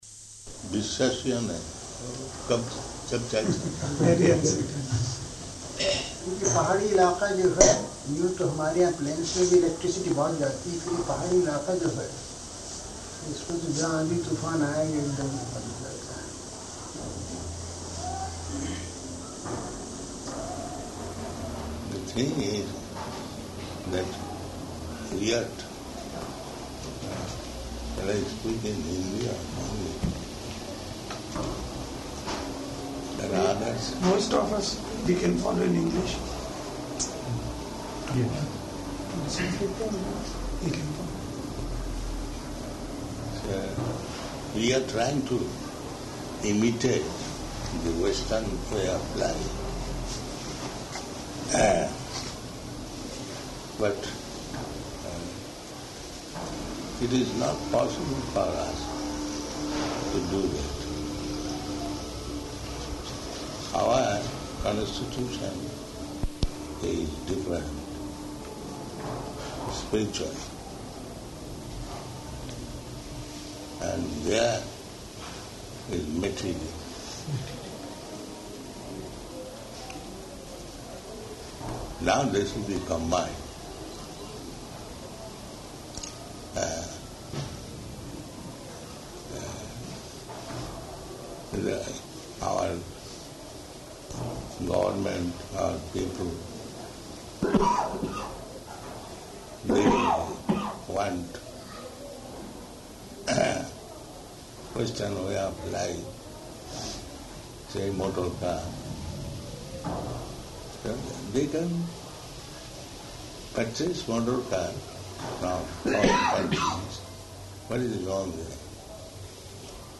Evening Darśana --:-- --:-- Type: Conversation Dated: May 9th 1977 Location: Rishikesh Audio file: 770509ED.HRI.mp3 Prabhupāda: [Hindi] Indian man (1): [Hindi] Prabhupāda: The thing is that we have to...